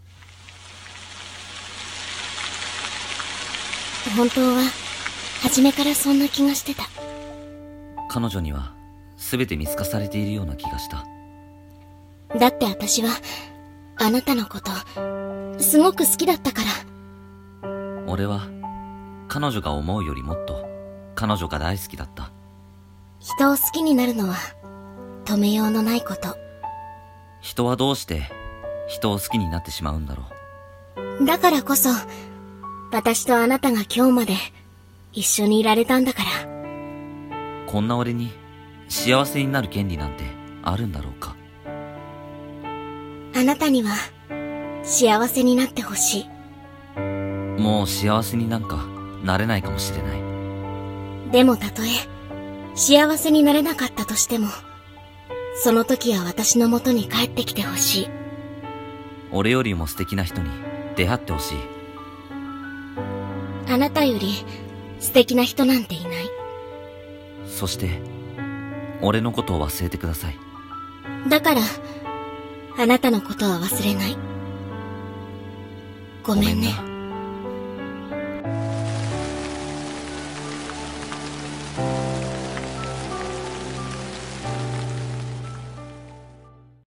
【朗読】
【コラボ声劇】